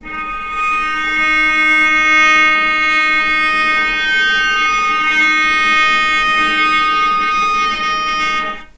healing-soundscapes/Sound Banks/HSS_OP_Pack/Strings/cello/sul-ponticello/vc_sp-D#4-mf.AIF at cc6ab30615e60d4e43e538d957f445ea33b7fdfc
vc_sp-D#4-mf.AIF